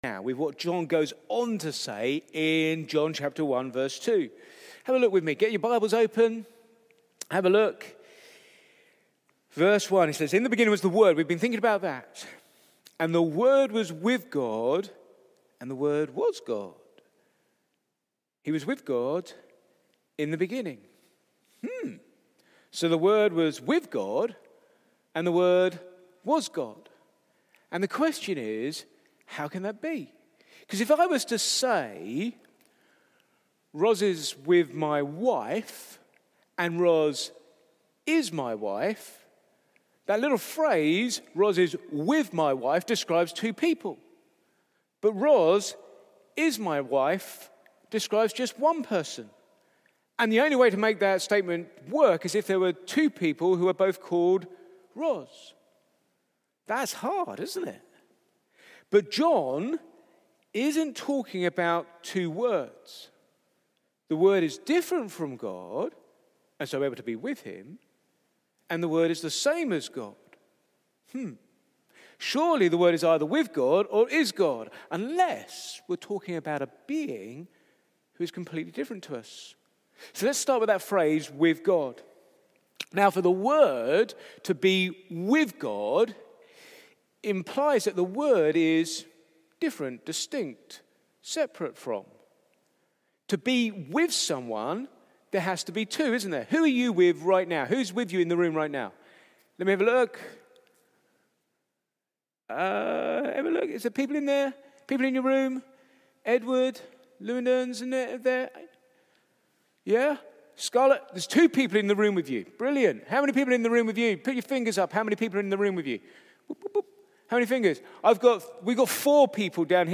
Back to Sermons The Word